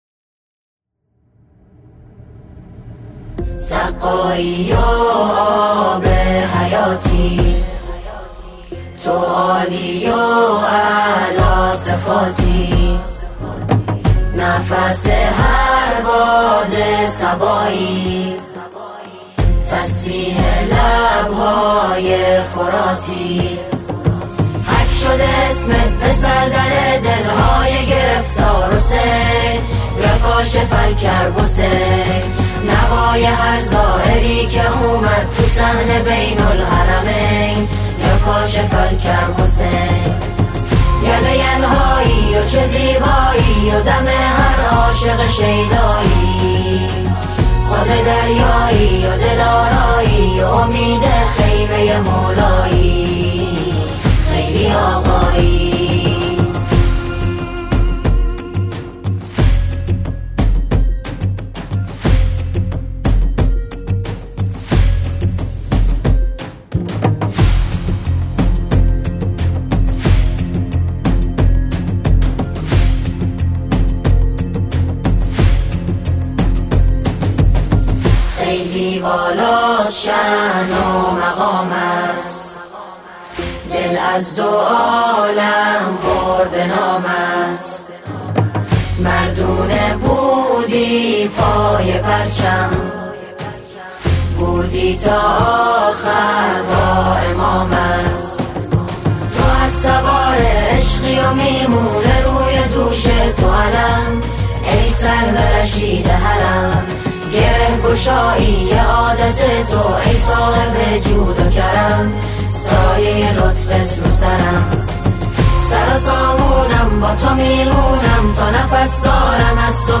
سرودهای امام حسین علیه السلام